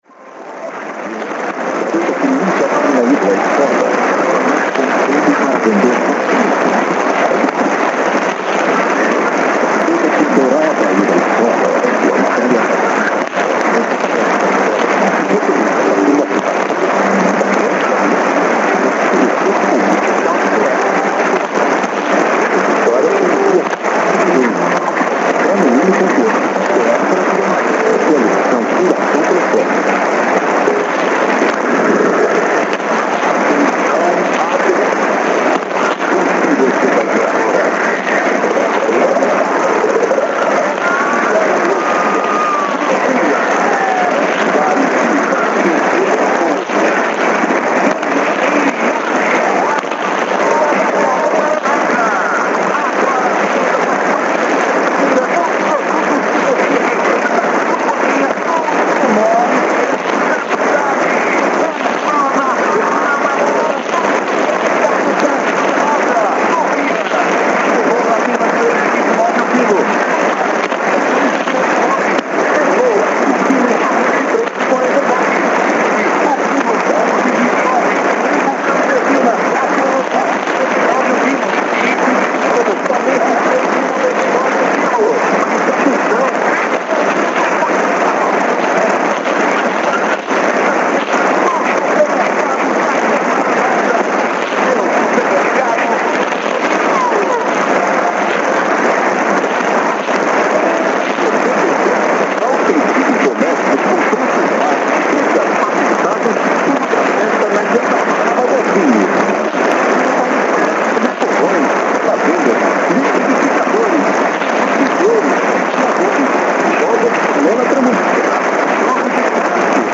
Trechos de áudios de captações realizadas durante o DXCamp Lorena 2010 ocorrido durante os dias 3 e 6 de junho de 2010.
As captações foram realizadas pelos participantes do encontro, utilizando os mais variados equipamentos e antenas.